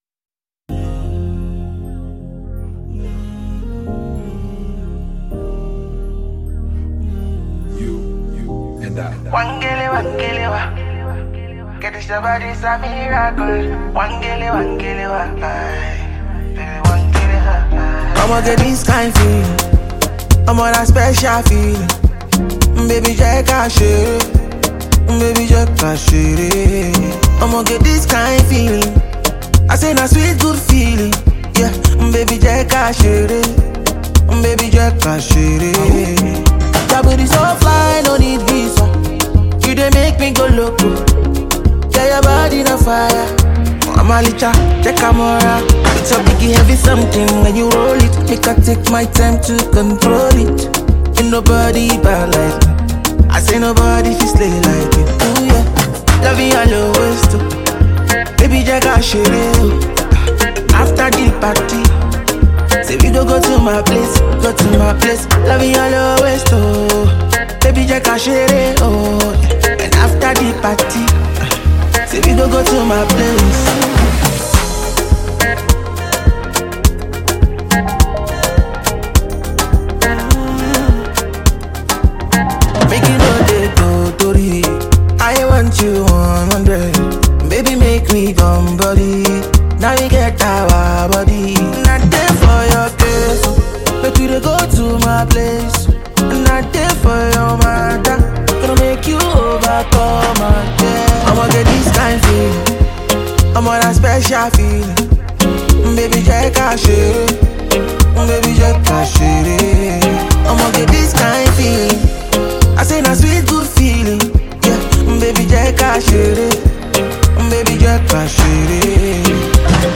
Nigerian Afro-Pop sensation
single